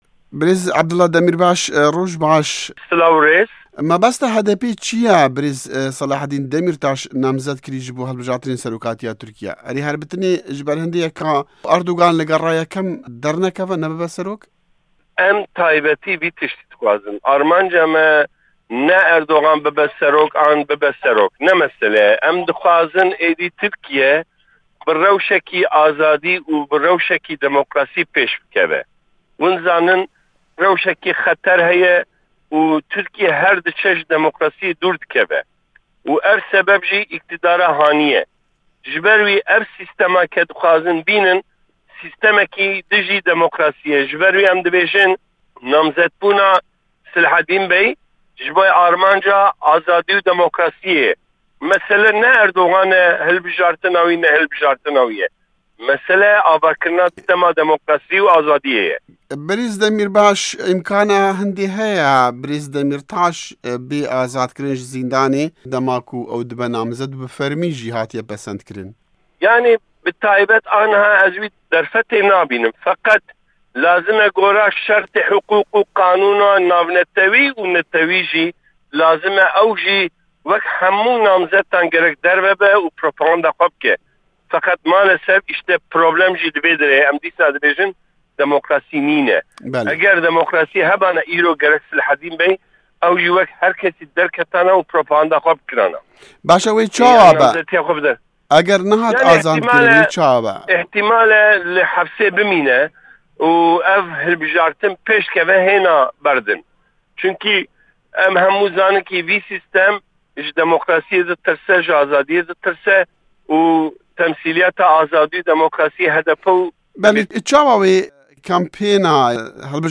Di hevpeyvînekê de digel VOA-Dengê Amerîka, Abdullah Demîrbaş, şaredarê berê yê Sûrê – Amedê got, namzetkirina Demîrtaş jibo azadî û demokrasiyê ye li Tirkiyê.